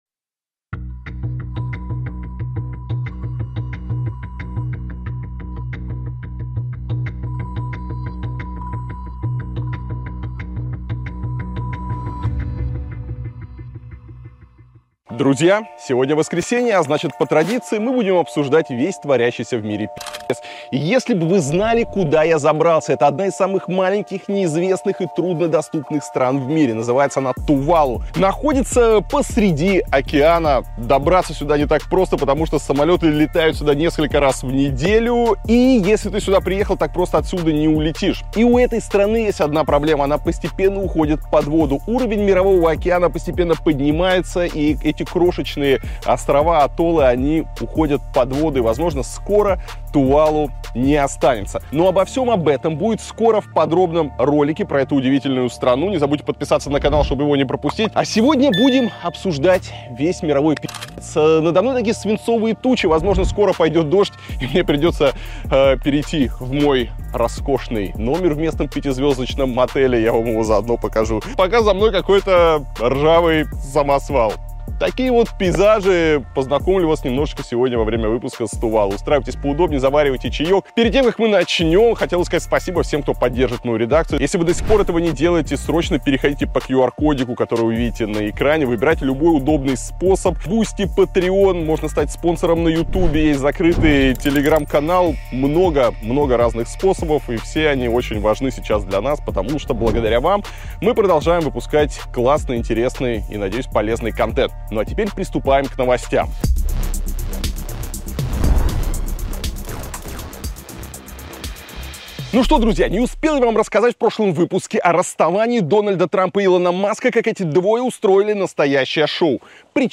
Эфир ведёт Илья Варламов